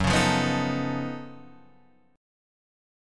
F# Minor Major 13th